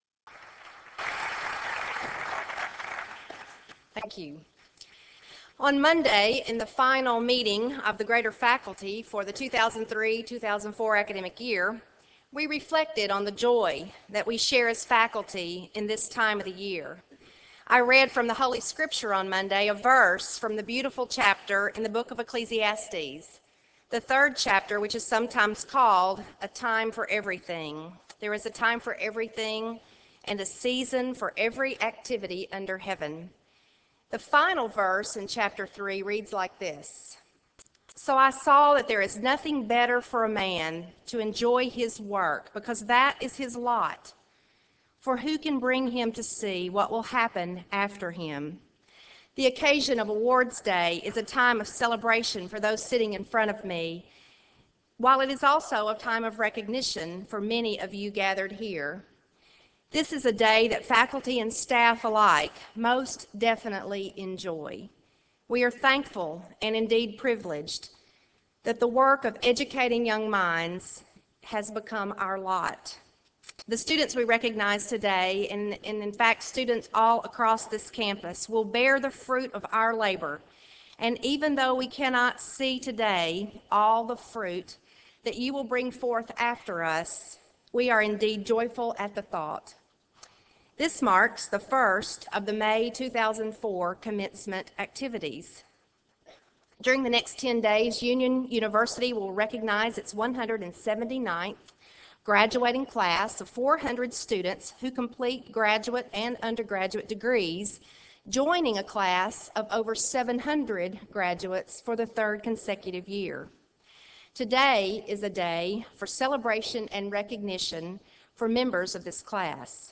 Chapel Service: Awards Day